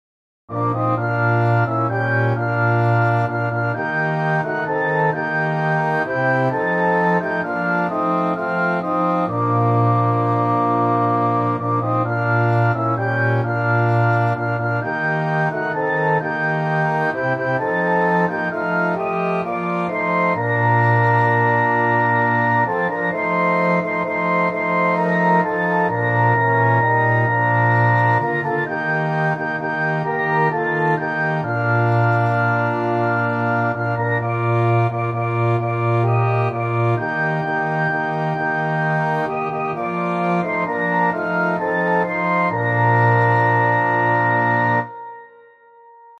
Orchestral Version